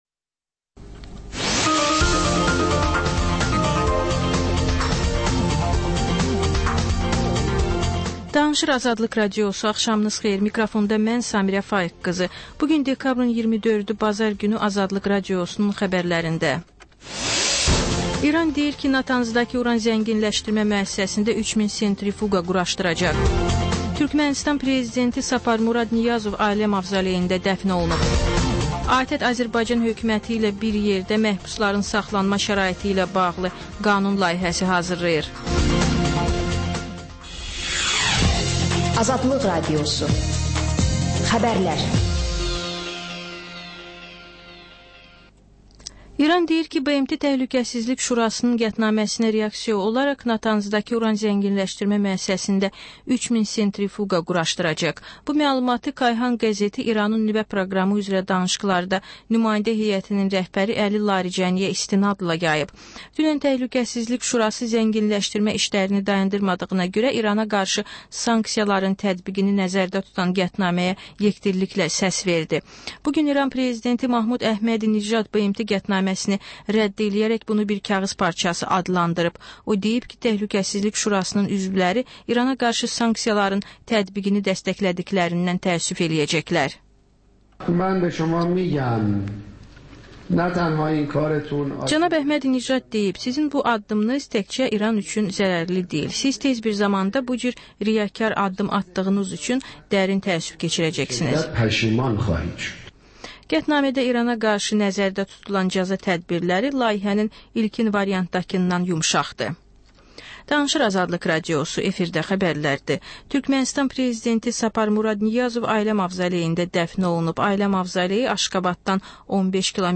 Xəbərlər, reportajlar, müsahibələr. İZ: Mədəniyyət proqramı.